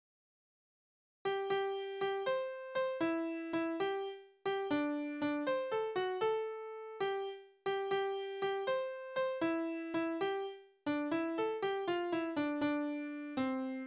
Tonart: C-Dur
Taktart: 6/8
Tonumfang: Oktave
Besetzung: vokal